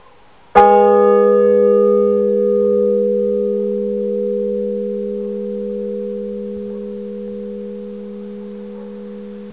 Die Glocke in dem folgenden Beispiel ist eine Stahlglocke in der St. Salvatoris-Kirche in Zellerfeld, Baujahr 1953.
Er ist am Anfang mit 35 dB etwas lauter als alle anderen Partialtöne.
Im Verlauf von einigen Sekunden verschwinden (Abb. 02) die oberen Partialtöne und am Ende bleibt nach 20 Sekunden nur noch der tiefste Ton (englisch hum = summen) übrig.
Abb. 01: zwei Schläge der kleinen Stahlglocke in Zellerfeld, St. Salvatoris.
Es gibt mehrere Obertöne, die tiefsten Töne schwingen am längsten.